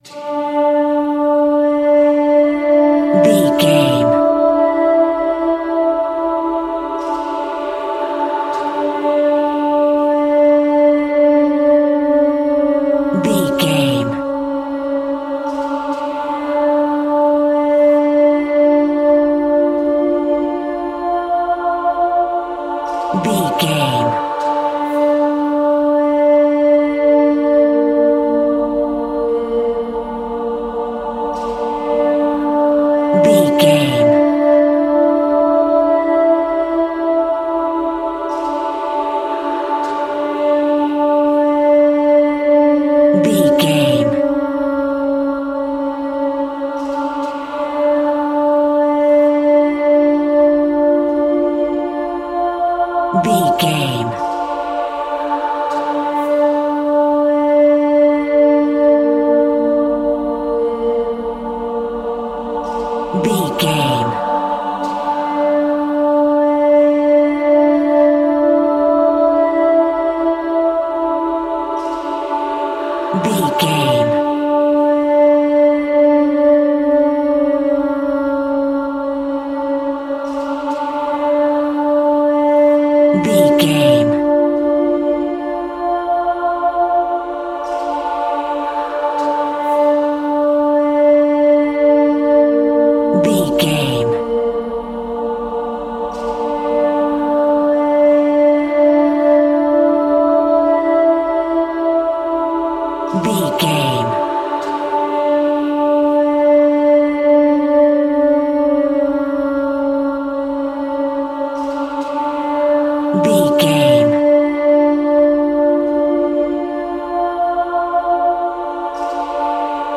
Aeolian/Minor
D
ominous
dark
haunting
eerie
vocals
horror
Eerie Voices